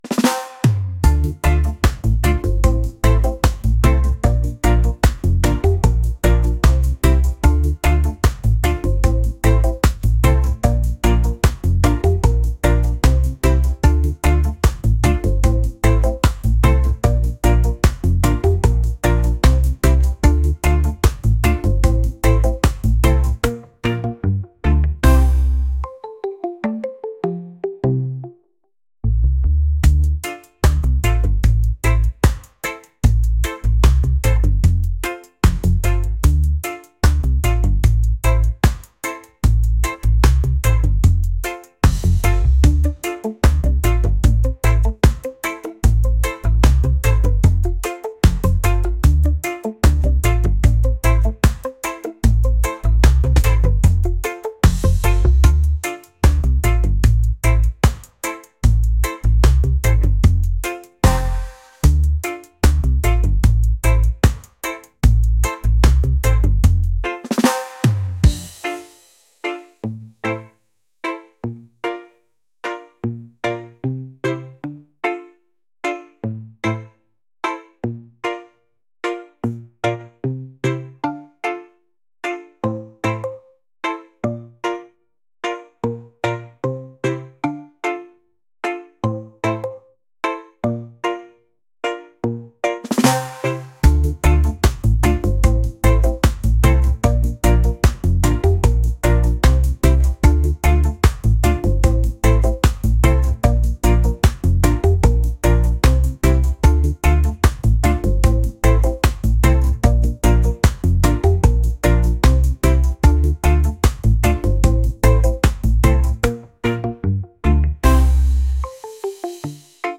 reggae | lounge | ambient